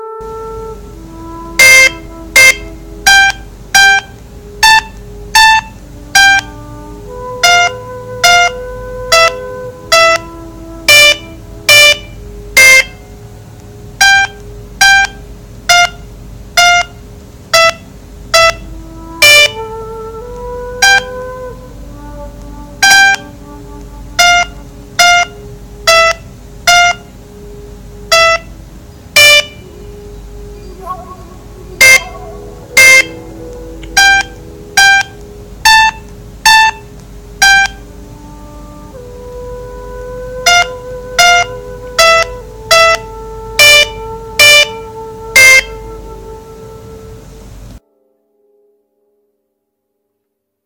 File:无名琴师演奏.mp3
无名琴师演奏.mp3.ogg